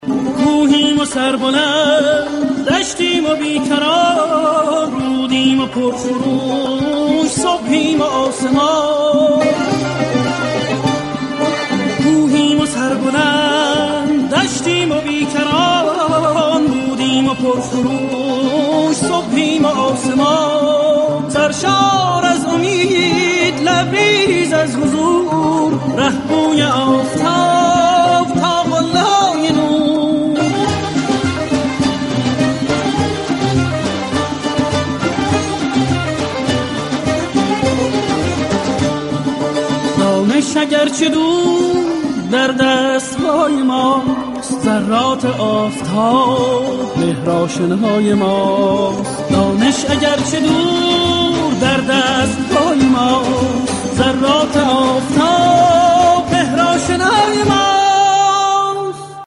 رینگتون شاد و با کلام